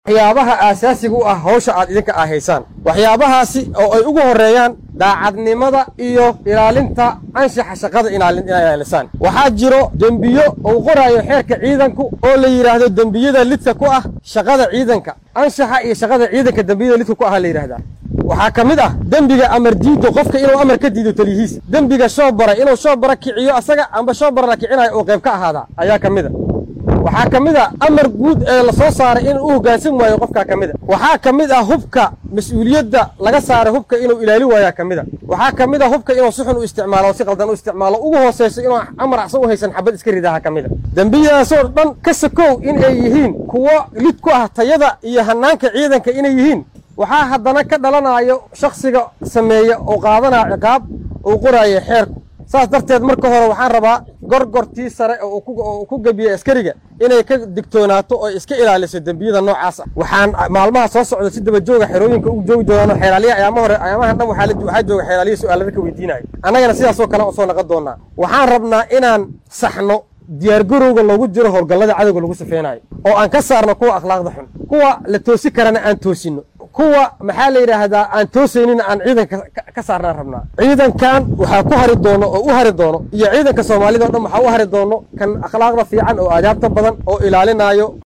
Guddoomiyaha maxkamadda darajada koowaad ee ciidamada qalabka sida ee Soomaaliya gaashaanle sare Xasan Cali Nuur Shuute oo ciidamada Gorgor ee xoogga dalka Soomaaliya kula hadlay magaalada Muqdisho ayaa shaaca ka qaaday in cadaalada la horgeyn doono askari waliba oo diida amarka taliyihiisa, islamarkaana sameeyo sifaalooyin ka baxsan shuruucada ciidanka.